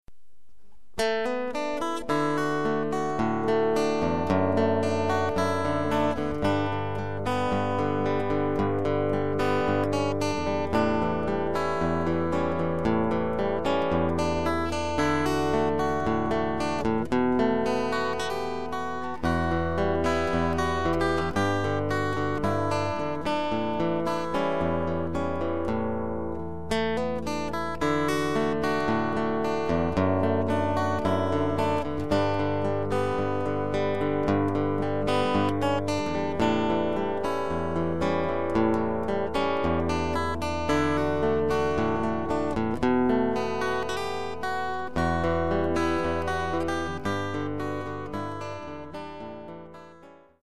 A great moment of  Picking style music.